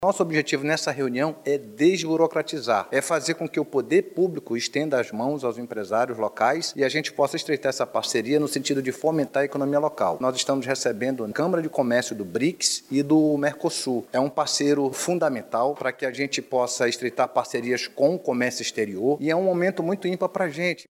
A reunião ocorreu no Mirante Lúcia Almeida, localizado no Centro Histórico de Manaus.
O secretário municipal de Finanças e Tecnologia da Informação, Clécio Freire, ressaltou a importância das parcerias público/privadas para a desburocratização da economia local.
SONORA-2-REUNIAO-ECONOMIA-MANAUS-.mp3